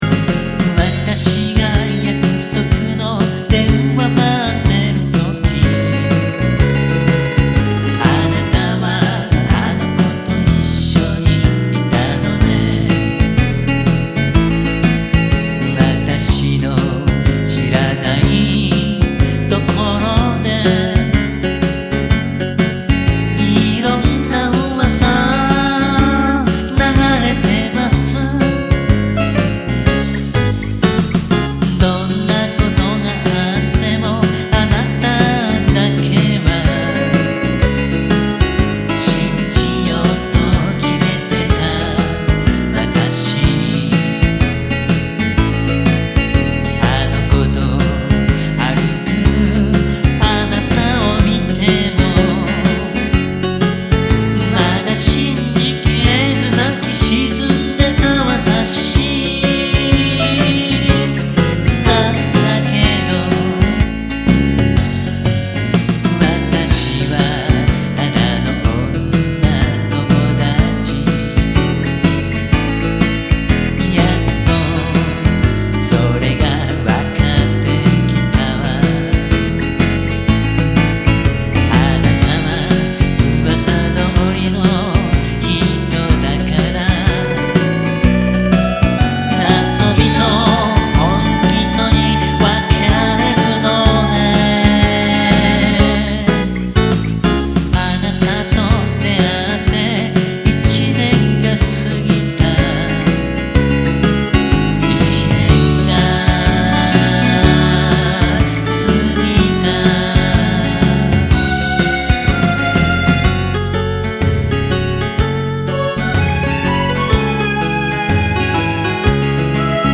ワンコーラス　　ＭＰ３ファィル・・・2.79ＭＢ　急に音が出ます！音量注意！！